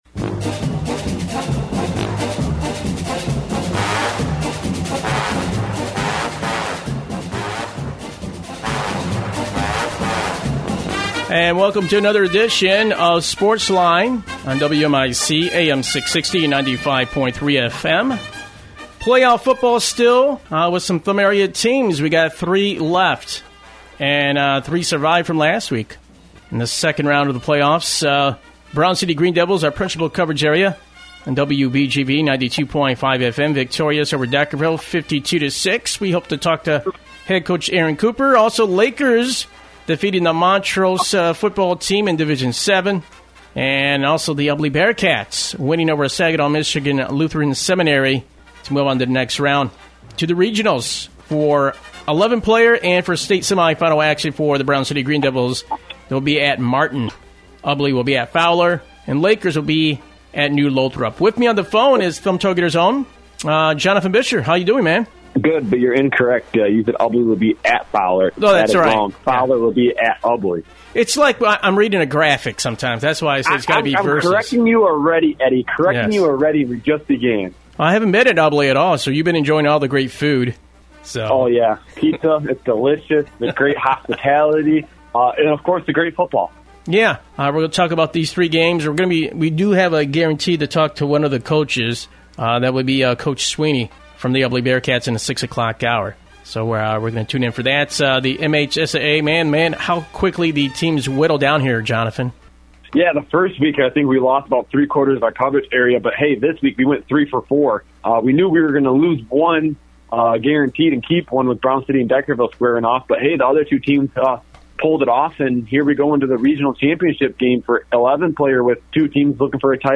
Features Interviews with Head Coaches
Sportsline-Radio-Show-Monday-Nov-7th-2022-Edition.mp3